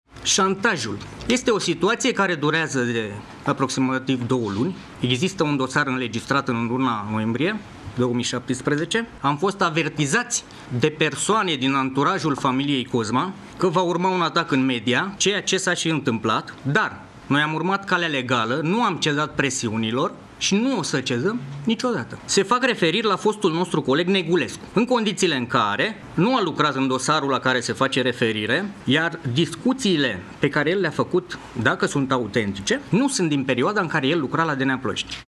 Sunt susţineri pe care le fac inculpaţii în dosarele în care sunt cercetaţi”, a spus Onea într-o conferinţă de presă.